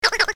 clock08.ogg